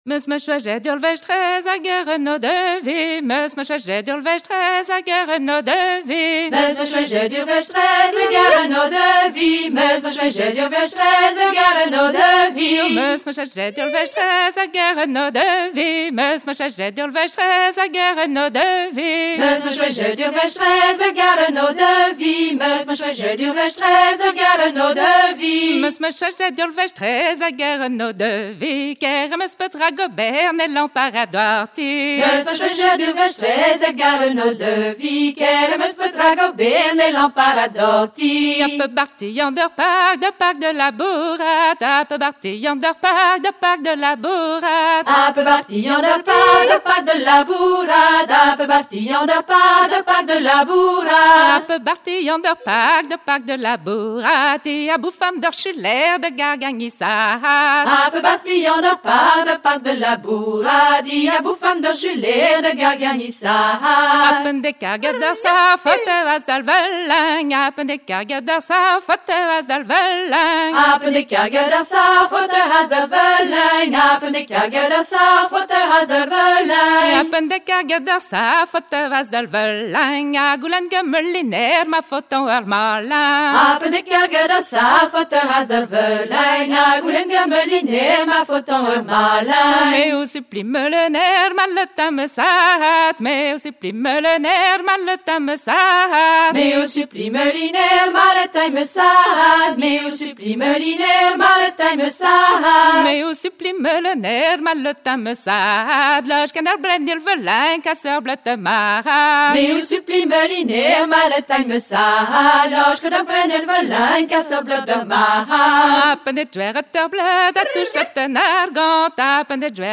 Laridé 8 Mestrez an odevi Laridenn 8 mestrez an odivi